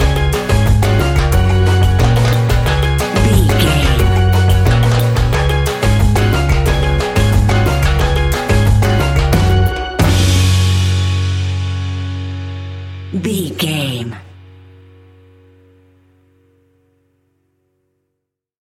Uplifting
Ionian/Major
E♭
steelpan
drums
percussion
bass
brass
guitar